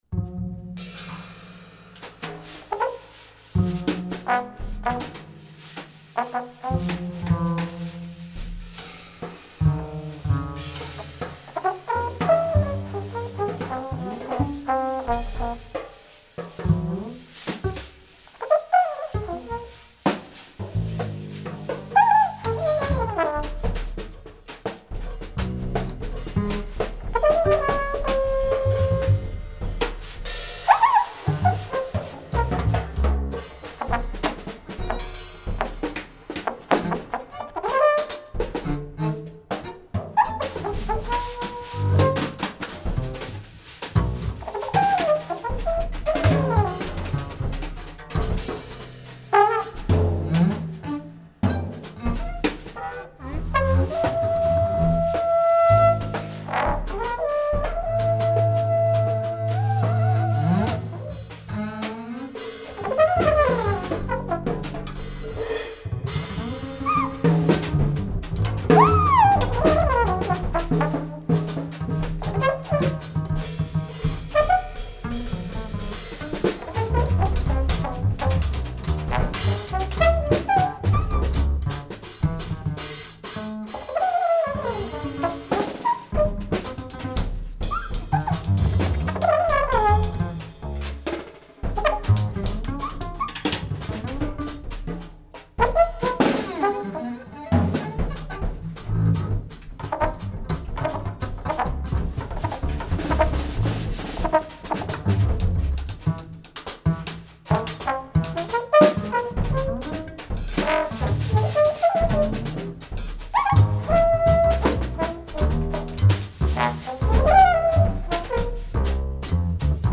trumpet, valve trombone, wood flute and conch shell
double bass
percussion